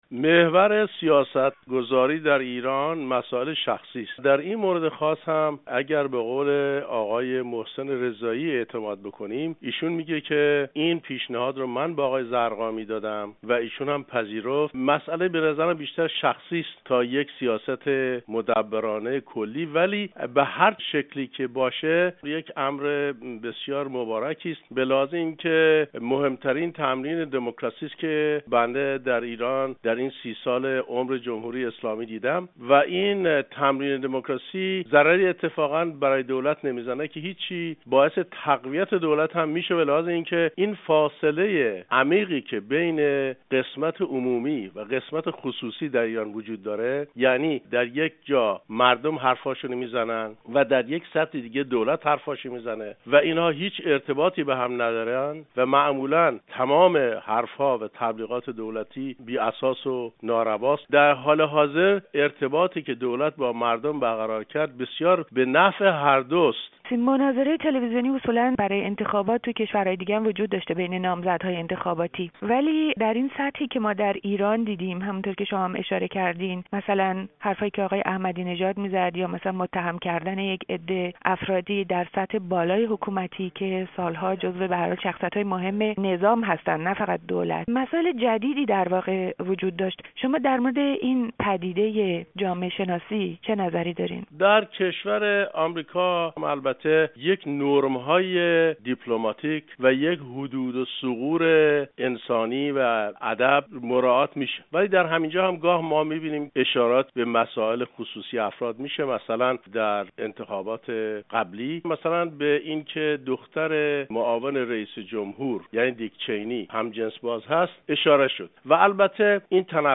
برای اولین بار مناظره‌های تلویزیونی؛ گفته‌ها و نگفته‌ها- گفت‌وگو